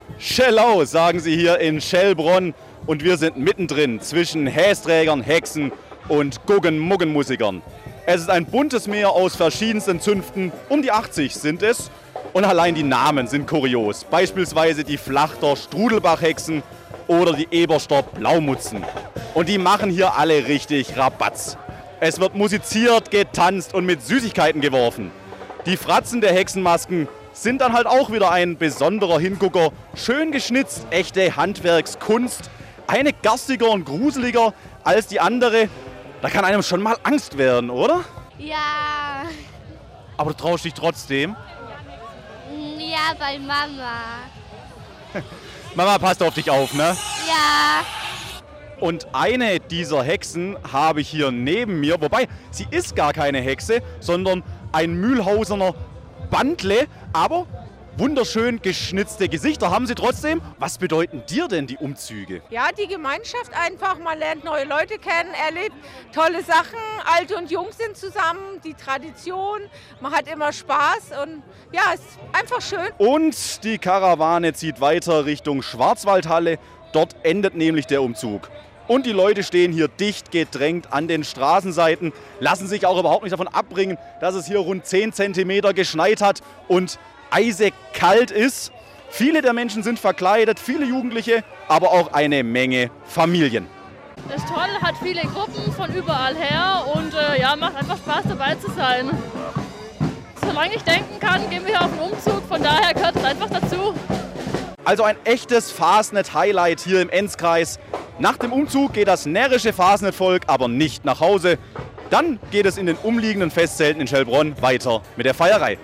Beste Stimmung bei Nachtumzug in Schellbronn